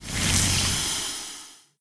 c_wnaga_hit2.wav